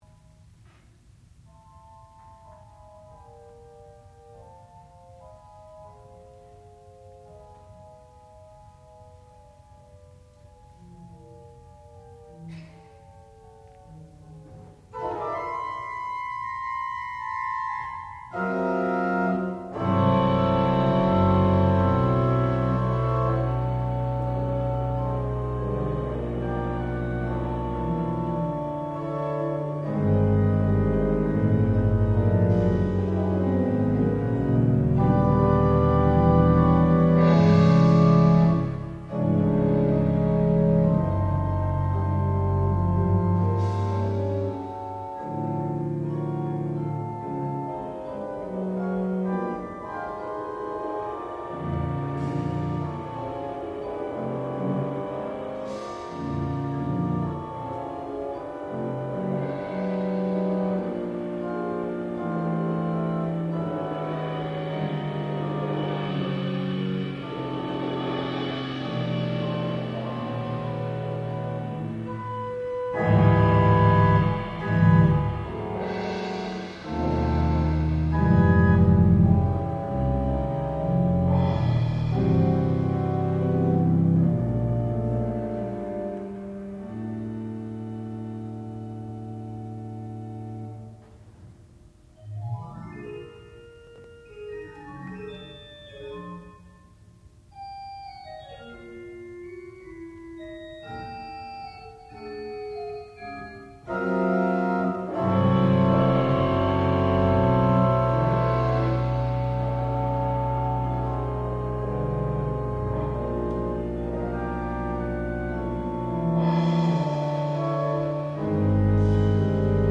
sound recording-musical
classical music
percussion
organ
Master's Recital